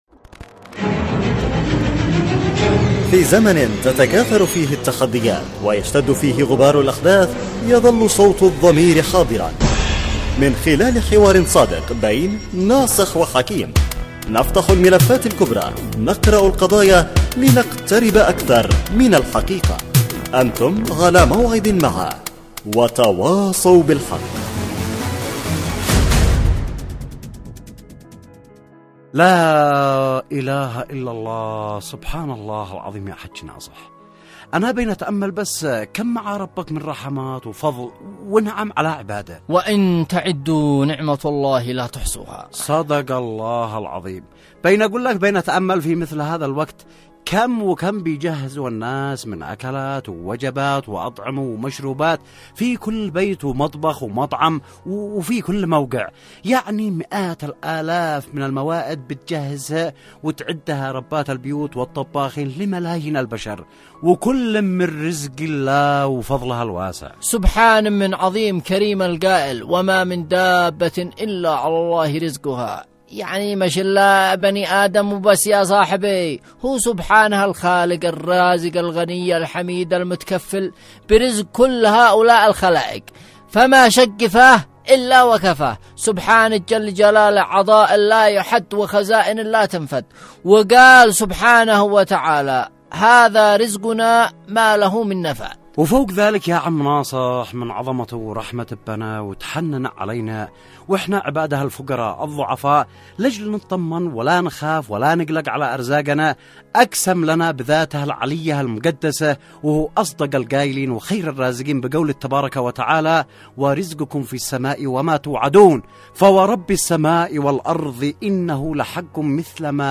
وتواصوا بالحق، برنامج إذاعي درامي يعمل كل يوم على طرح إشكالية و مناقشة مشكلة تهم الجميع وبعد جدال بين الطرفين يتم الاحتكام بينهم الى العودة الى مقطوعة للسيد القائد تعالج المشكلة